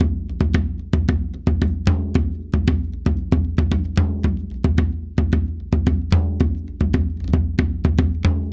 BON112SURDO.wav